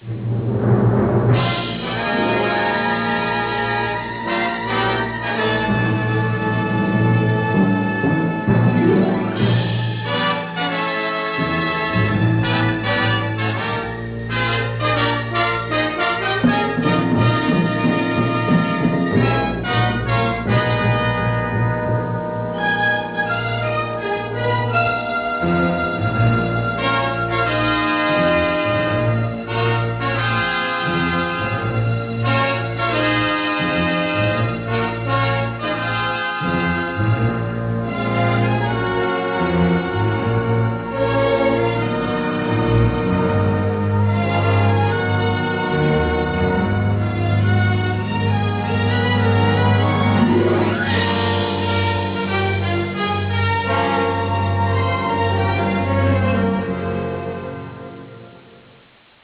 Direzione musicale:
Original Track Music